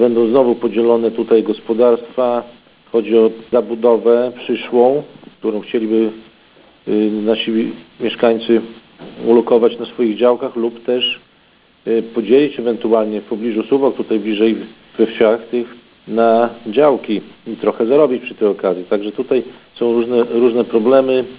– Tak, jak przy każdej liniowej inwestycji, ktoś będzie pokrzywdzony – mówi Mariusz Grygieńć, wójt gminy Szypliszki.